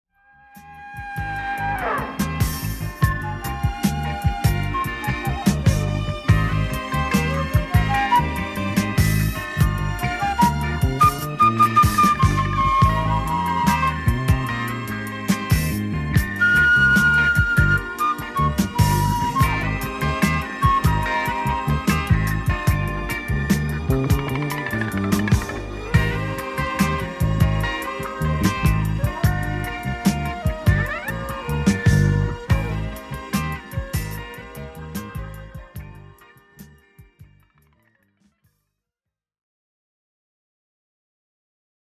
ジャンル(スタイル) DISCO / FUNK / SOUL